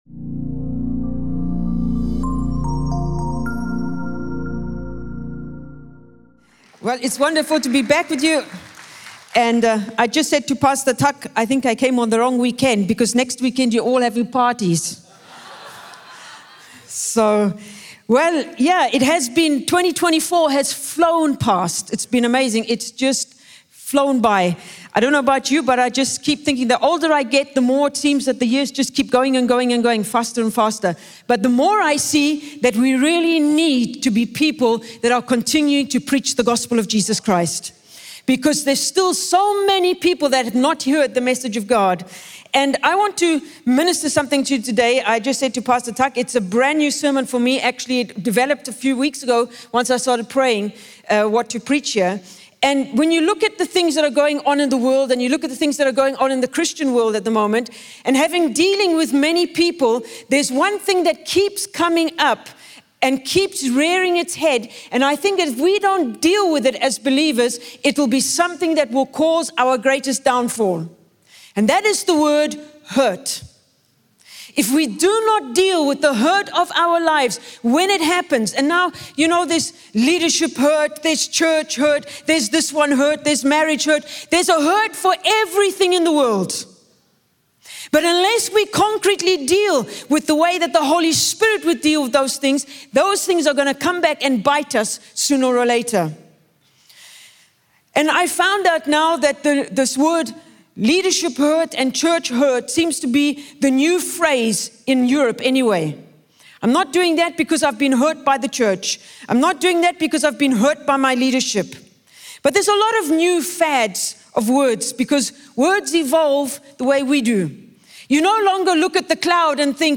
Weekly audio sermons from Cornerstone Community Church in Singapore